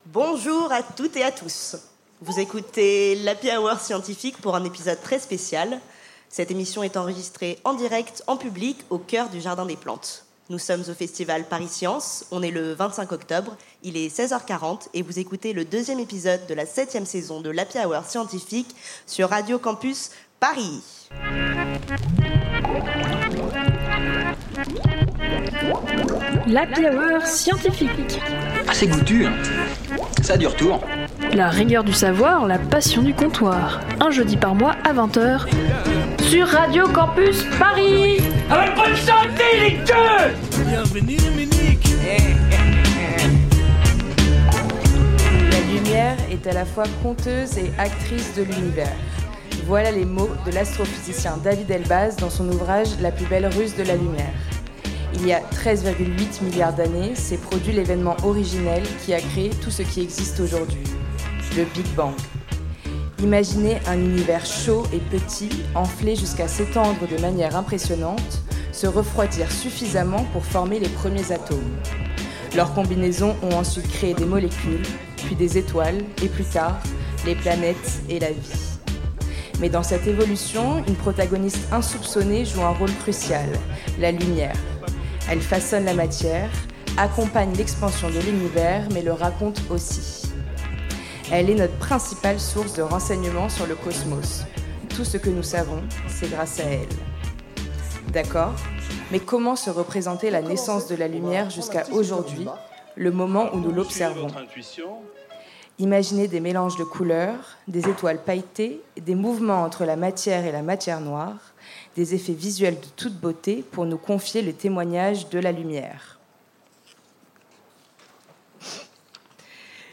Une émission spéciale enregistrée en public à l'Agora du Museum National d'Histoire Naturelle dans le cadre du Festival Pariscience